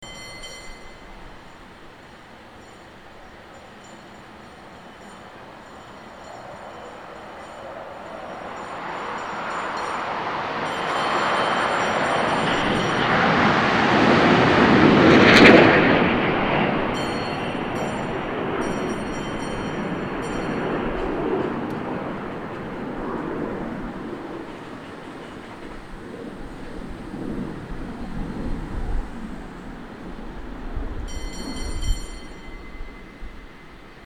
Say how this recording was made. Fleet of ear. . . Ever wonder what a Navy F/A - 18 Hornet sounds like when it goes past you at just under Mach 1 and you hang a microphone out your window?